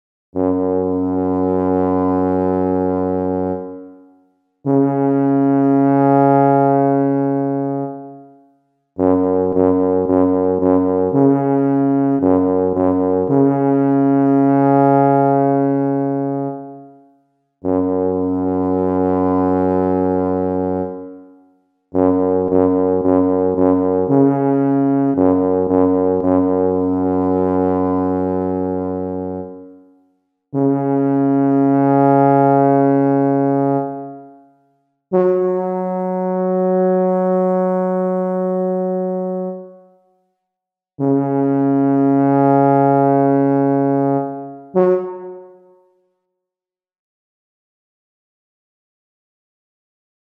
sygnał przeznaczony do grania na bawolim rogu